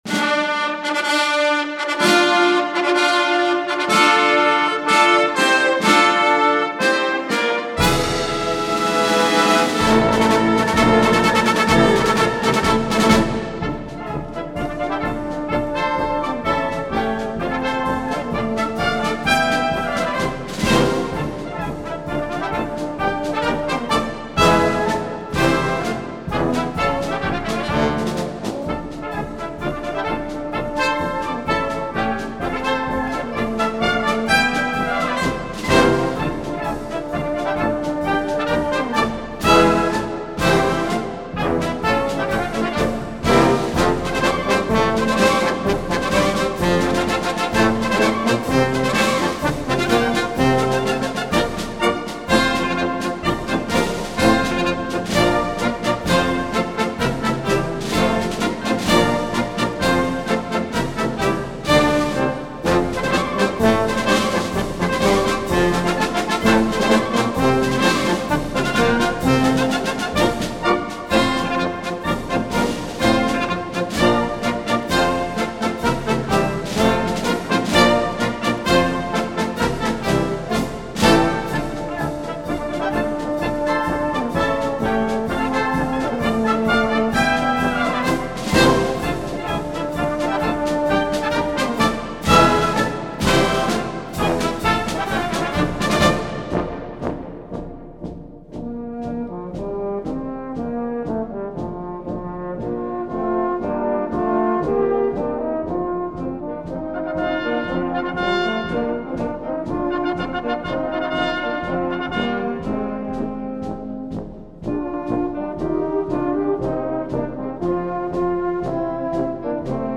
Український марш